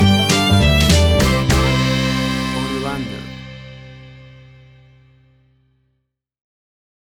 Fun and upbeat American fiddle music from the country.
WAV Sample Rate: 16-Bit stereo, 44.1 kHz
Tempo (BPM): 100